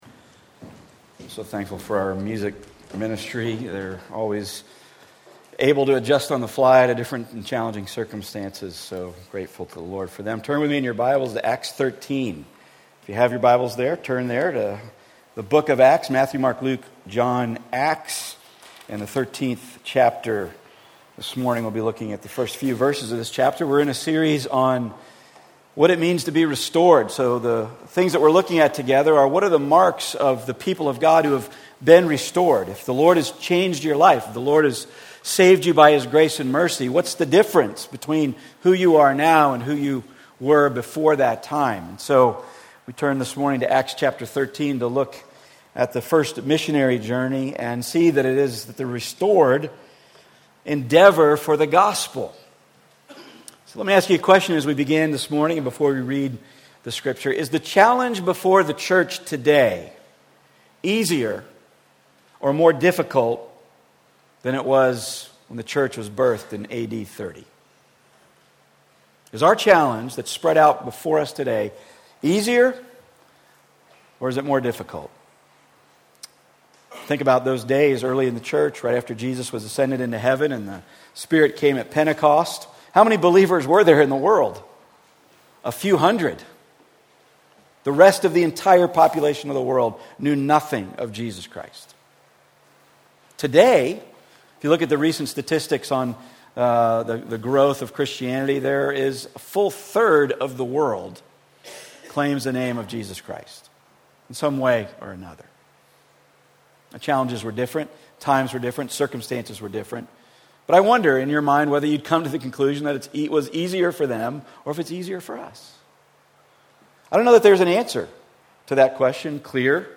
Passage: Acts 13:1-5 Service Type: Weekly Sunday